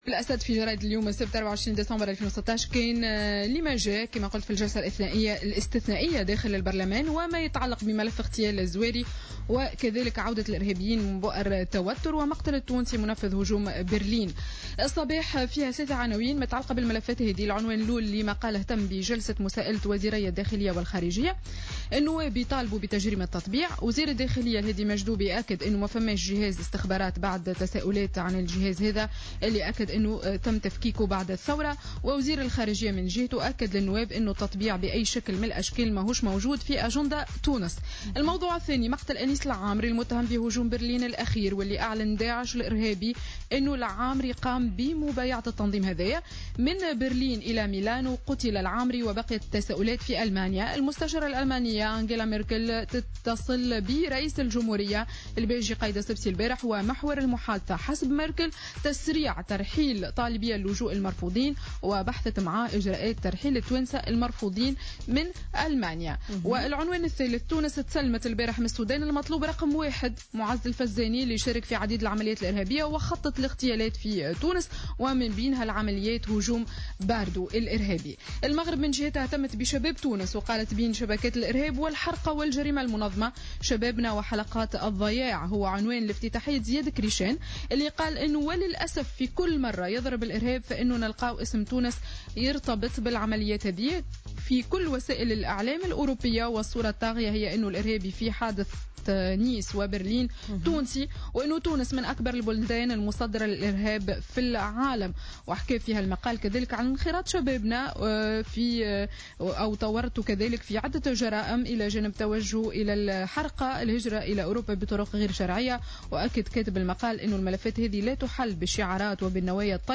Revue de presse du samedi 24 décembre 2016